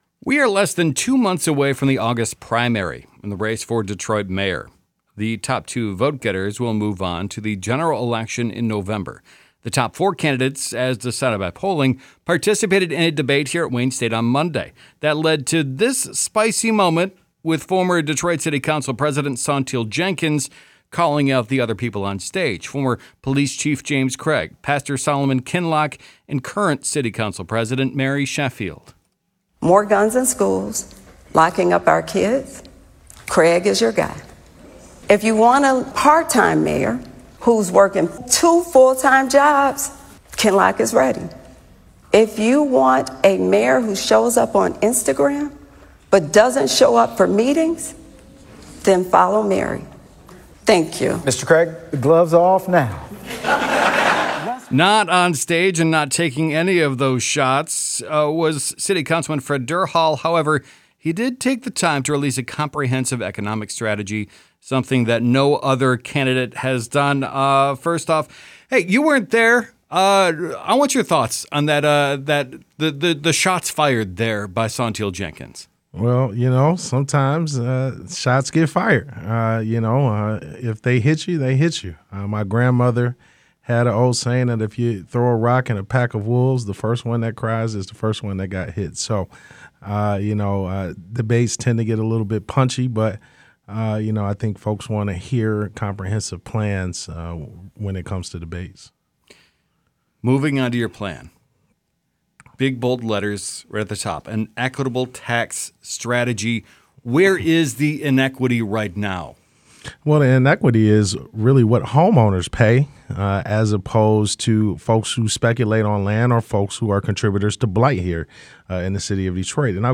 Durhal joined WDET’s All Things Considered – Detroit on Wednesday to talk about the motivation behind his economic platform and his reaction to Monday’s debate.
The following interview has been edited for clarity and length.